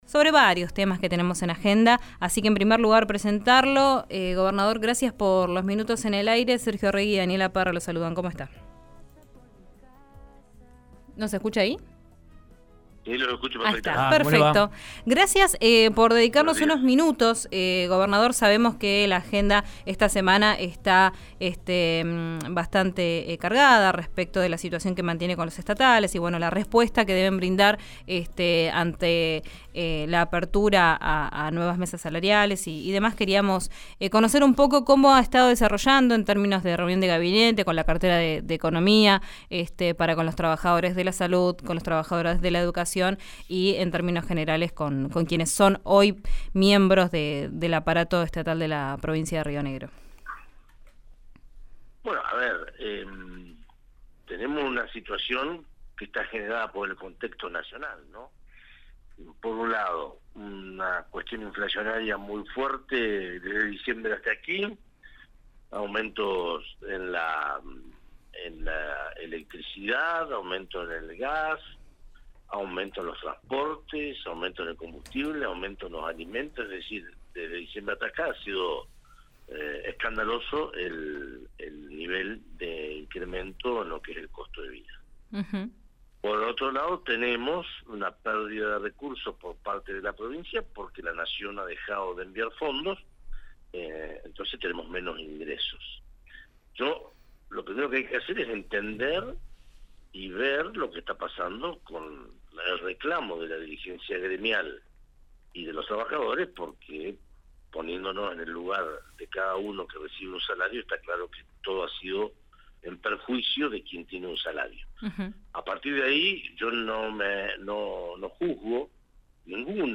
El gobernador de Río Negro conversa con RÍO NEGRO RADIO: ataque de Irán a Israel, Ley Bases, Pacto de Mayo, convenio colectivo y paritarias.
El gobernador de Río Negro, Alberto Weretilneck, habló con RÍO NEGRO RADIO luego de repudiar el ataque de Irán contra Israel.